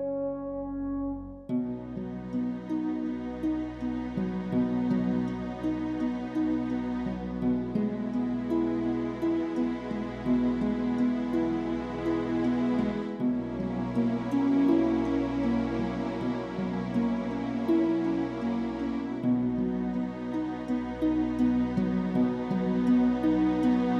no Backing Vocals Musicals 5:37 Buy £1.50